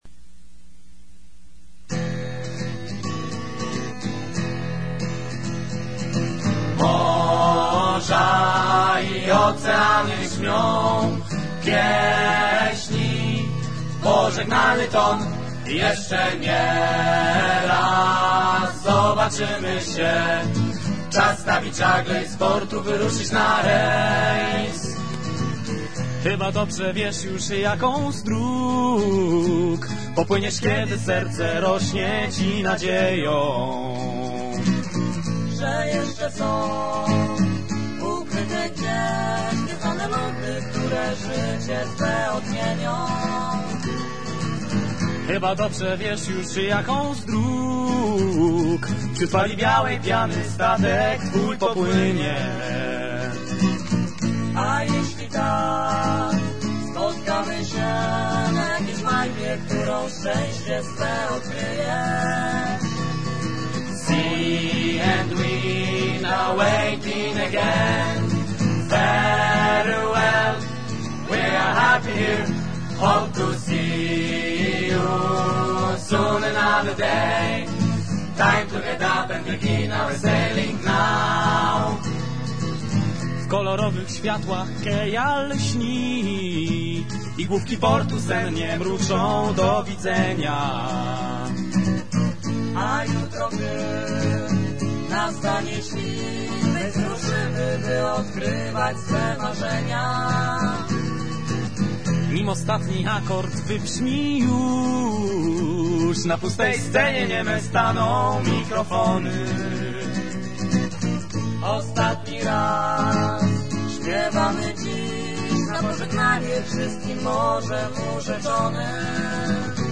the crew’s penultimate song in each performance